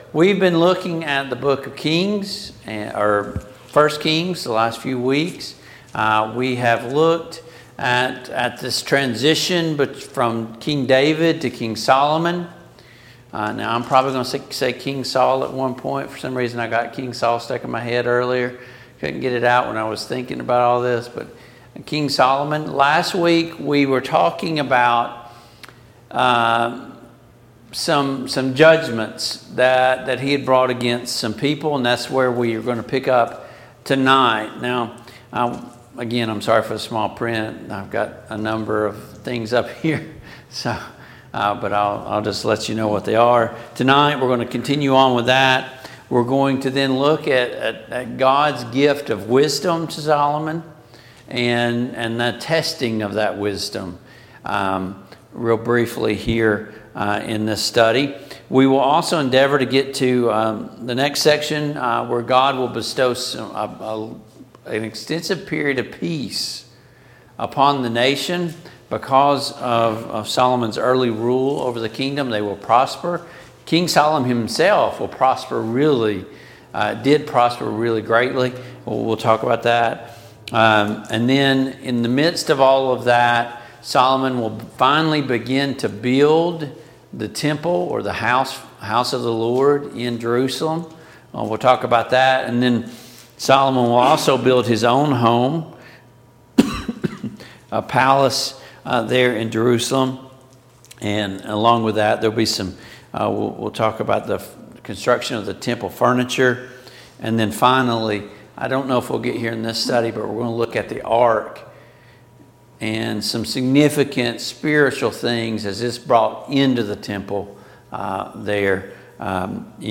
Service Type: Mid-Week Bible Study Download Files Notes Topics: Godly wisdom , King Solomon , Wisdom « Is Hell a real place?